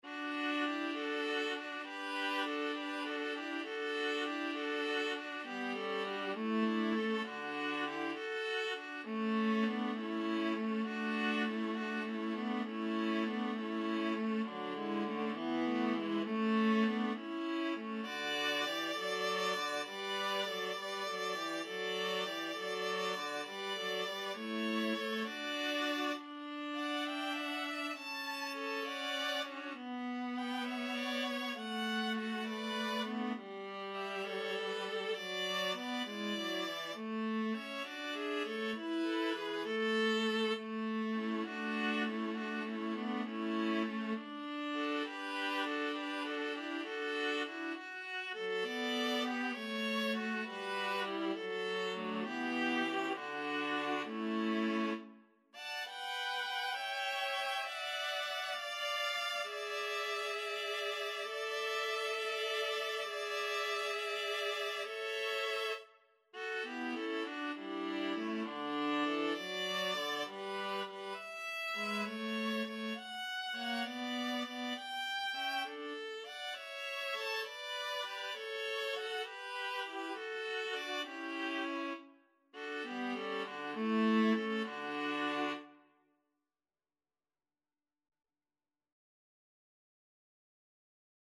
12/8 (View more 12/8 Music)
Largo
Viola Trio  (View more Intermediate Viola Trio Music)
Classical (View more Classical Viola Trio Music)